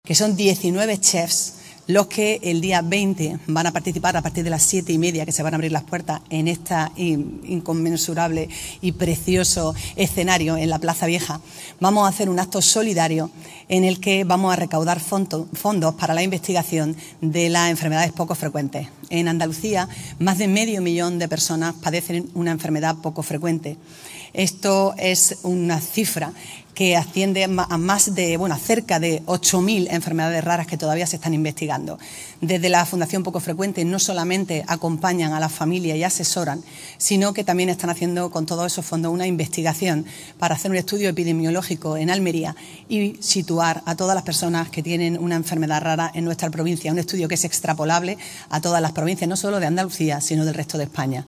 ALCALDESA-SABORES-QUE-SUMAN.mp3